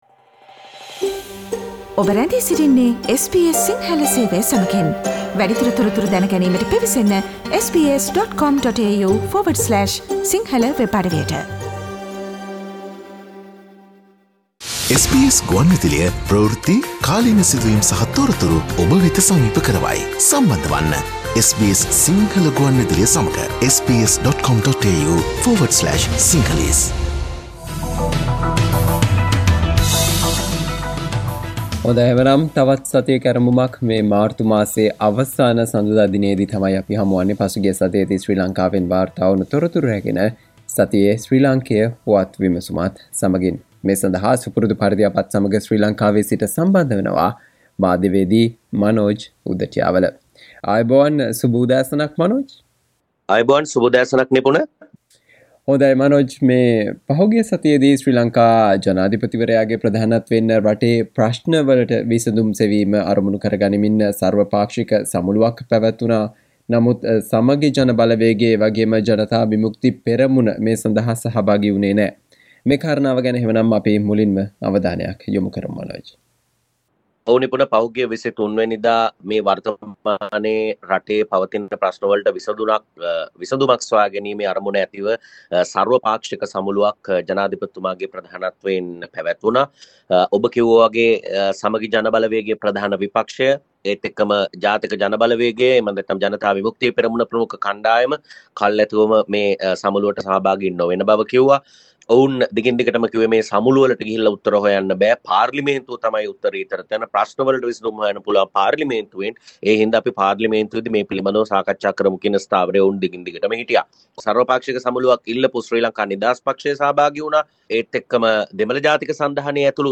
සතියේ ශ්‍රී ලාංකීය පුවත් සමාලෝචනයට